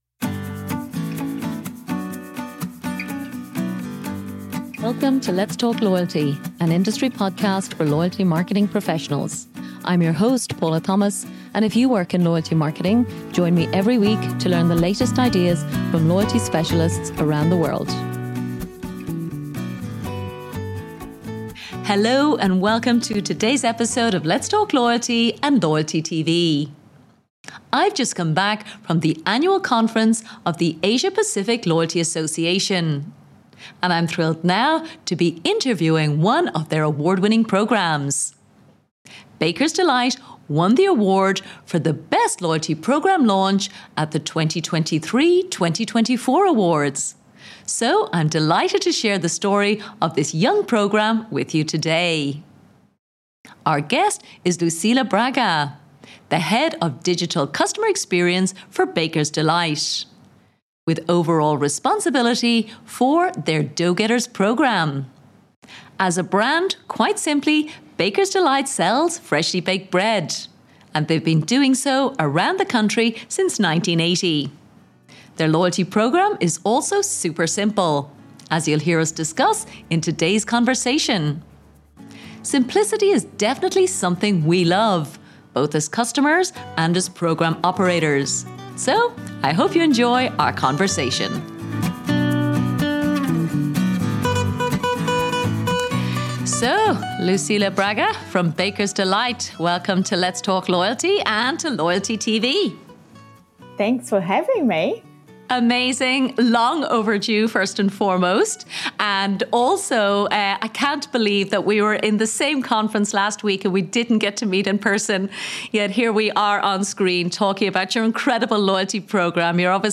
Their loyalty programme is also super simple as you’ll hear in today’s conversation. Listen to hear the power of simplicity as a winning formula for both customers and program operators.